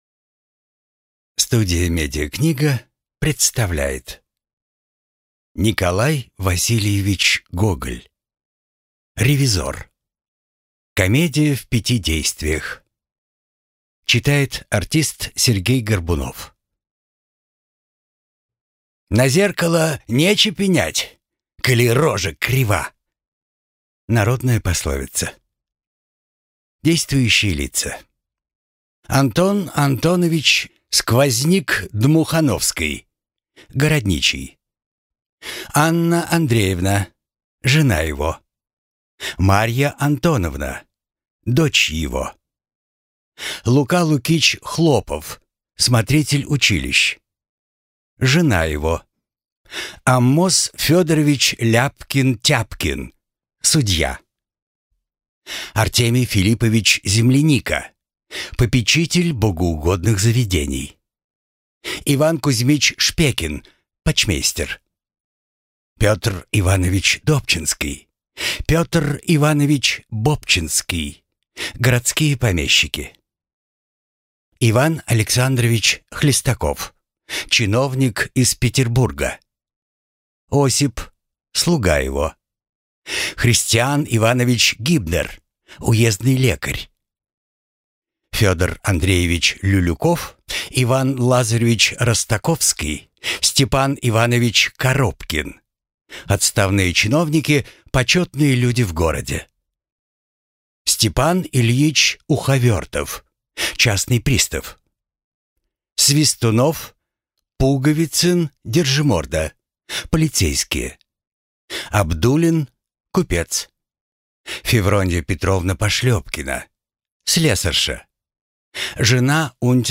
Аудиокнига Ревизор | Библиотека аудиокниг